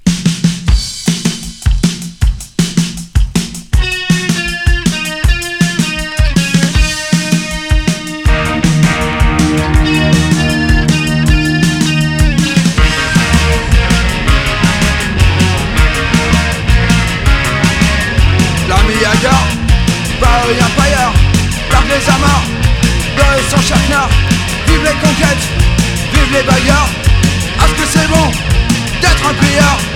Oi